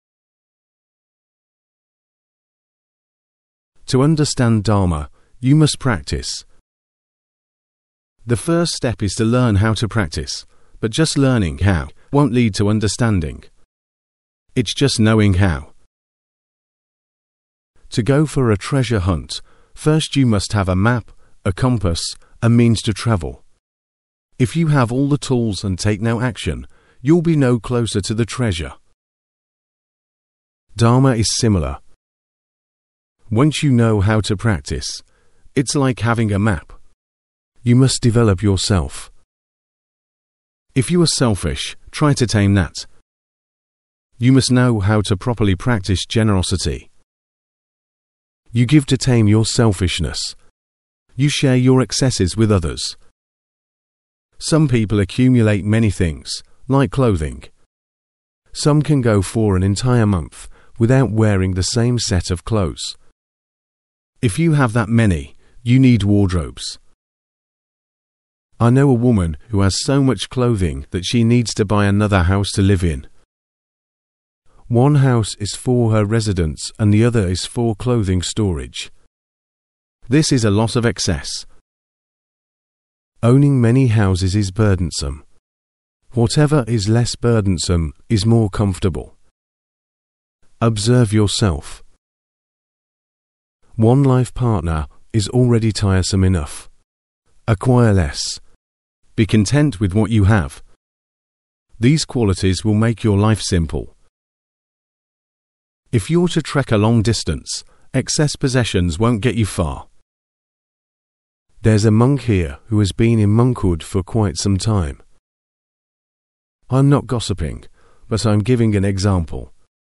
Edited and Narrated - Qualities for Dhamma Apprenticeship - 1 October 2023